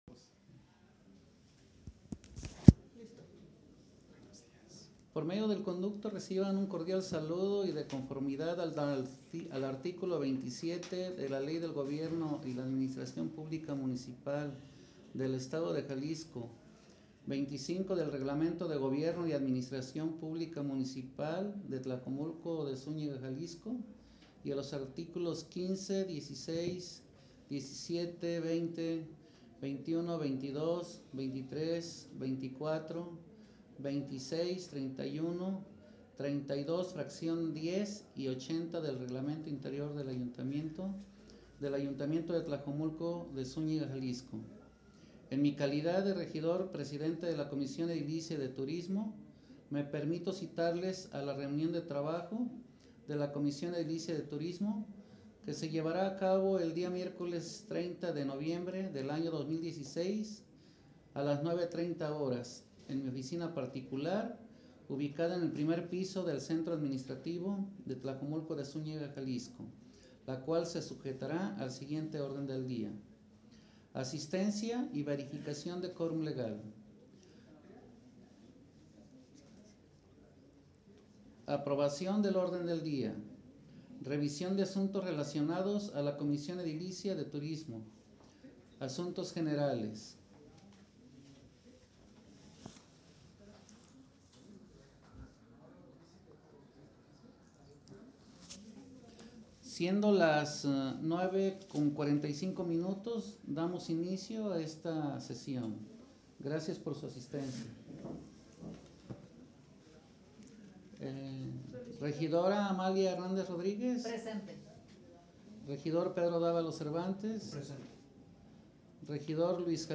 Reunión Comisión Edilicia de Protección Civil y Bomberos. 02 de Mayo de 2017